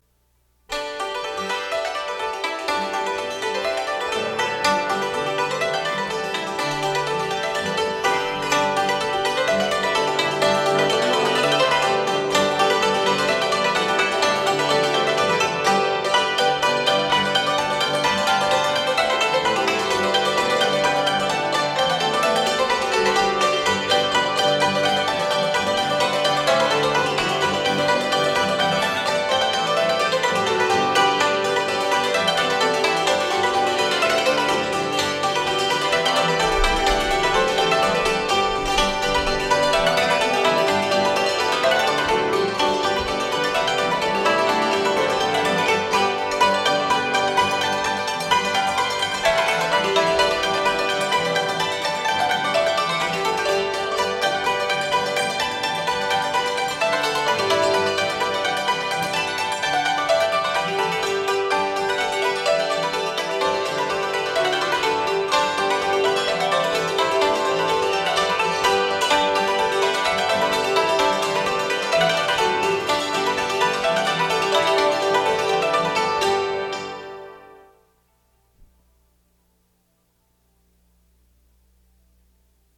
It was recorded in what was for many years the fair office.